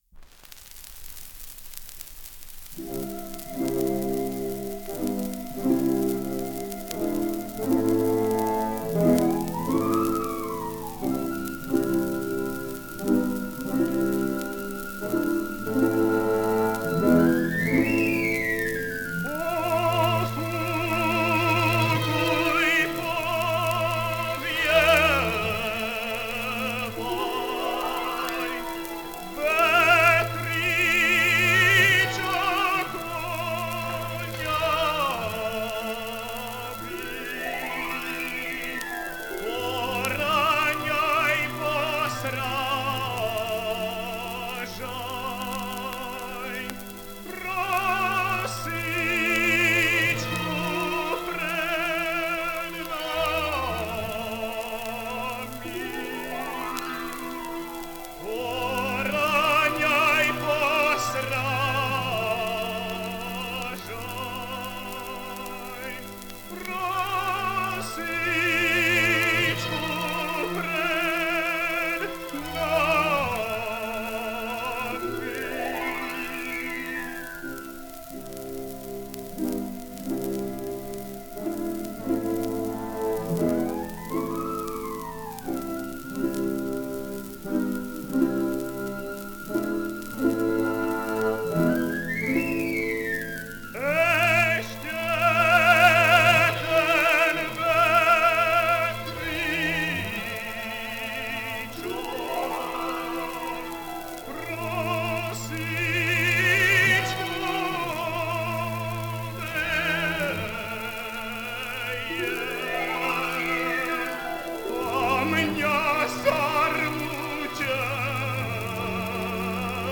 Dátum a miesto nahrávania: 15.10.1933, Praha
(hlavný názov) Z filmu Matice slovenskej „Zem spieva“ (podnázov) Dátum a miesto nahrávania: 15.10.1933, Praha Popis Mužský spev so sprievodom orchestra.
slovenská ľudová pieseň
Štefan Hoza (1906 - 1982) - spev
Orchester Národného divadla v Prahe - orchester